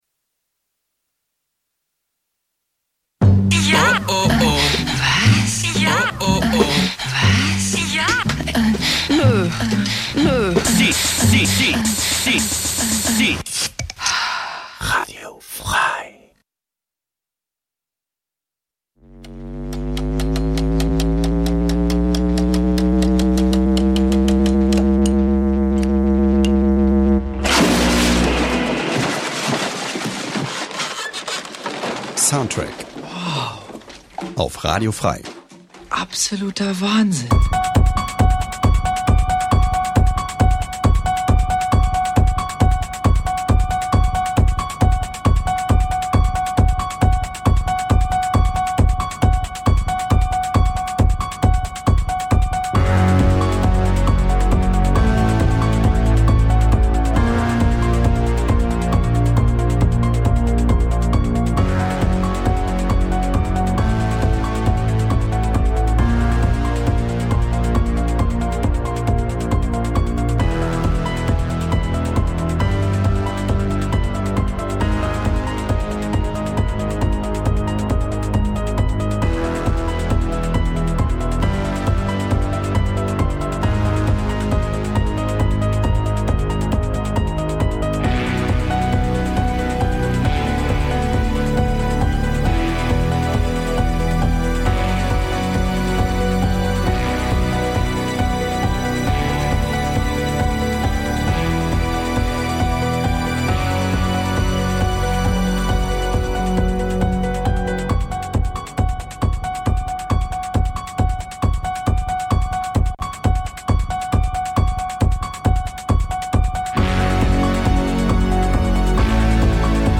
Wöchentlich präsentieren wir ausgesuchte Filmmusik.